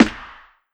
Knock Snare.wav